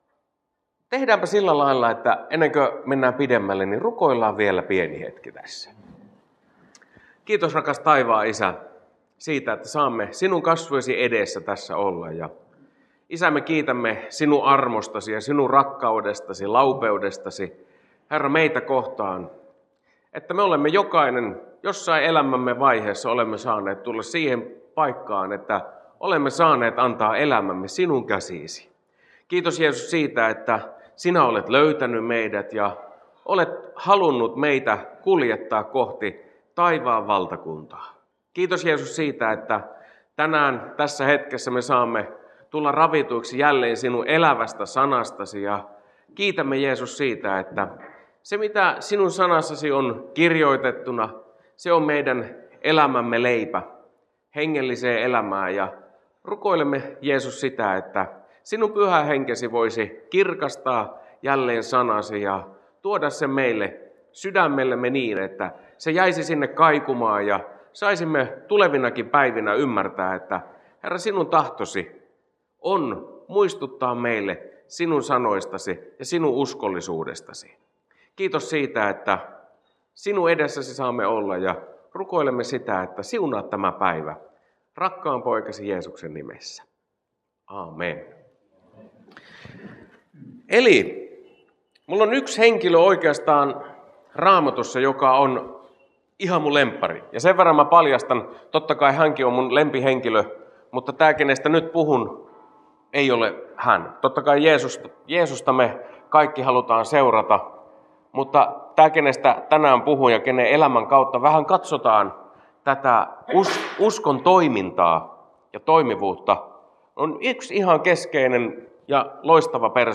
puhe Alajärvellä pidetyssä Gideon päivässä 21.3.2026.